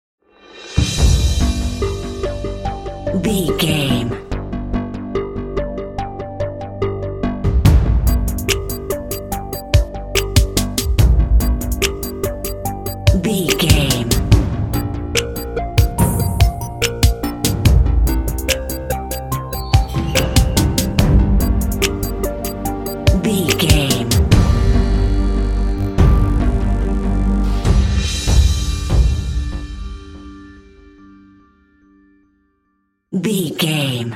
Aeolian/Minor
tension
suspense
synthesiser
drums
contemporary underscore